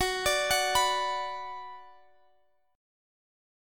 Listen to F#sus4#5 strummed